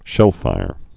(shĕlfīr)